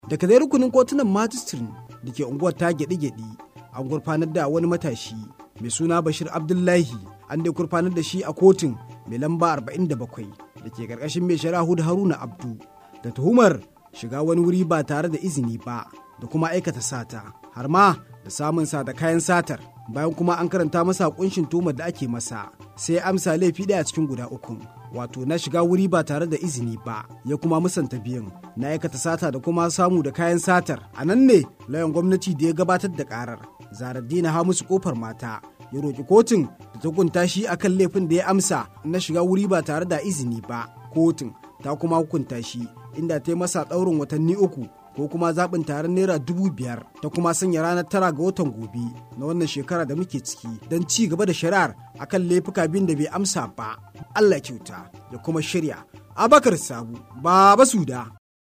Rahoto: Wani matashi ya gurfana a kotu a kan zargin sata